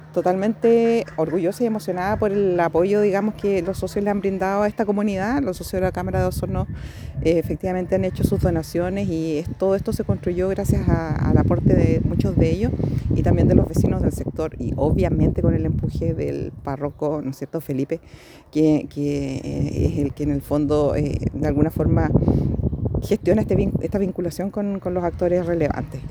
Con emoción